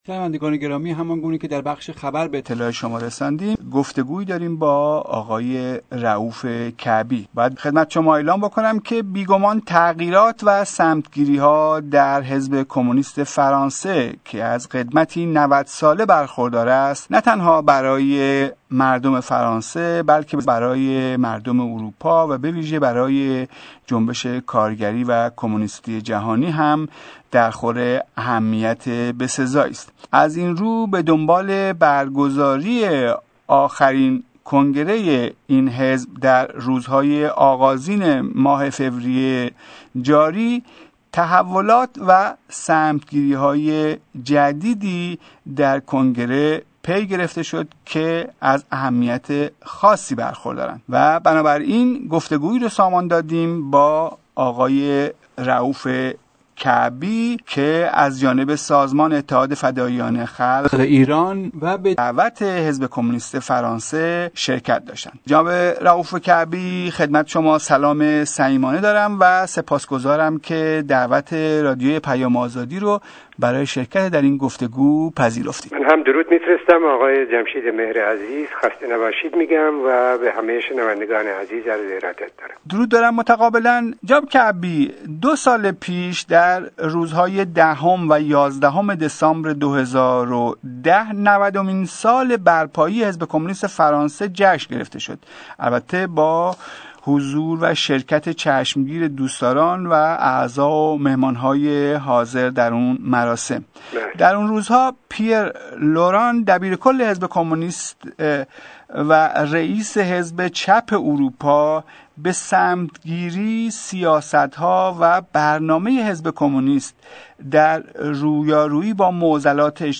گقت و گو